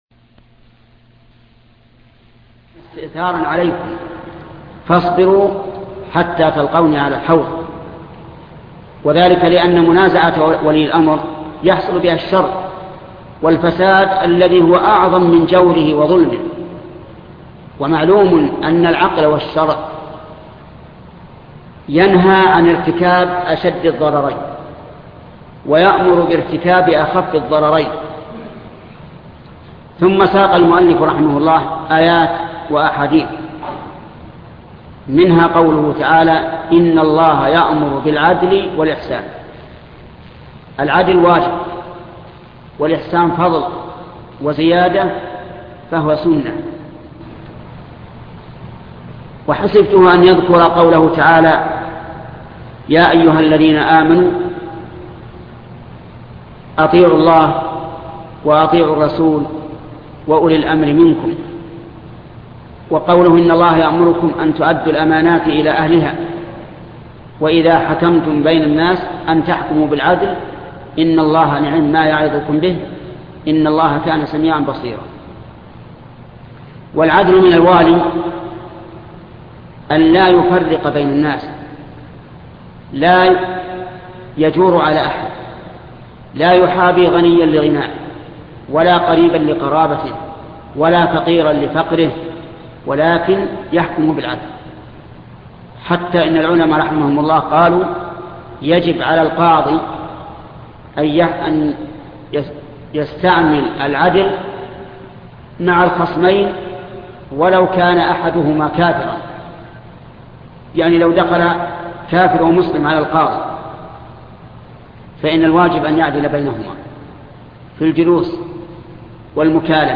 شرح رياض الصالحين الدرس السادس والتسعون